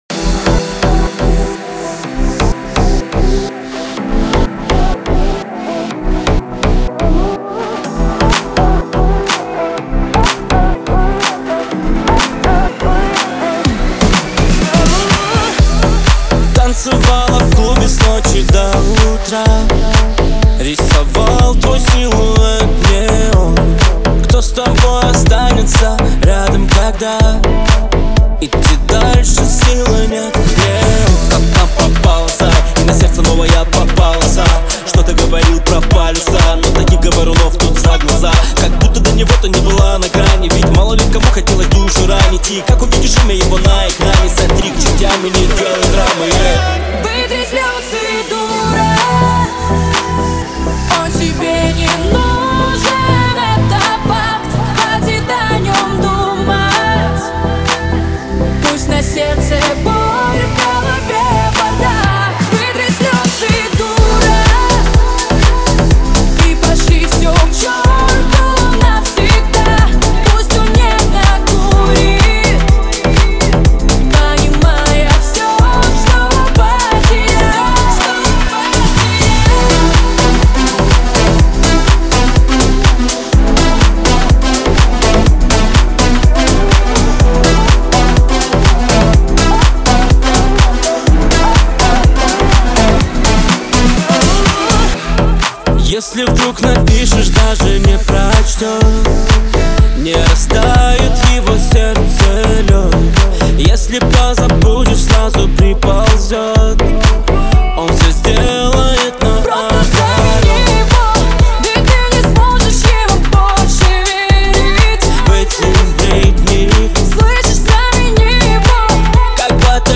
сочетая мелодичный вокал с ритмичными битами.